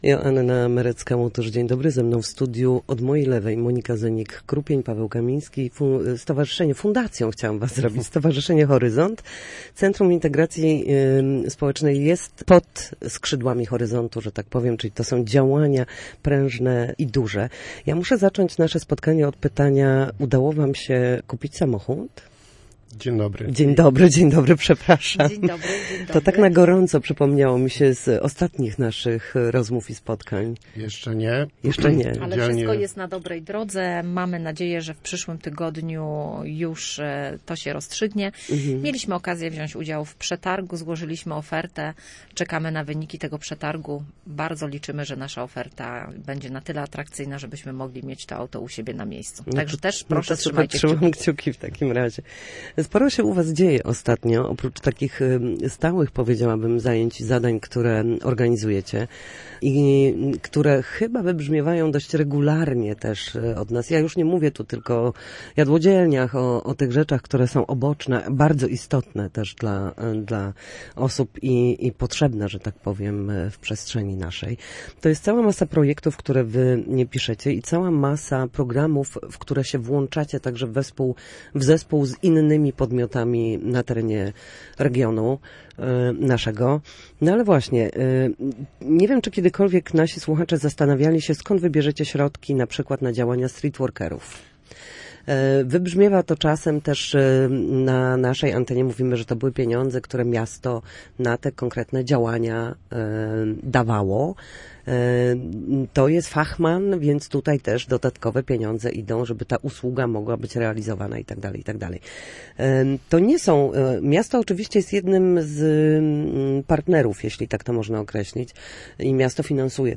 W rozmowie przybliżyli działania, projekty i formy wsparcia realizowane przez słupską organizację.